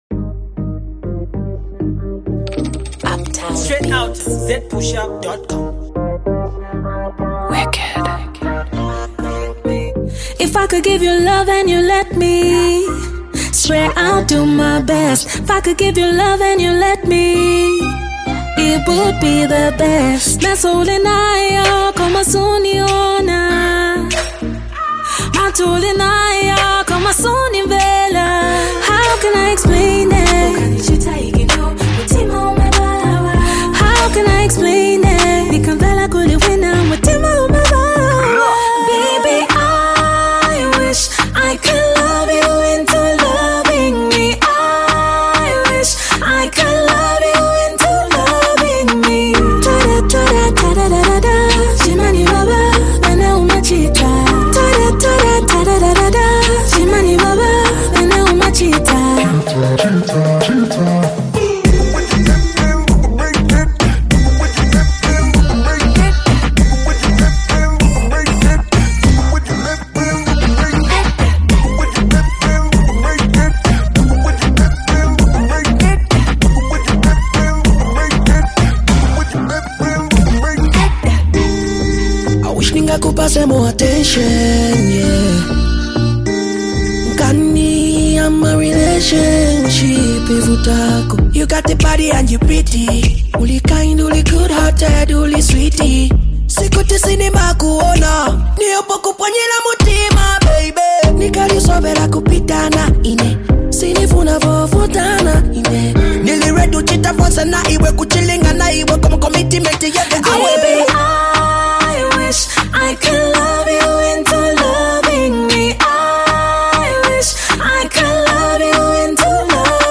female artist
dancehall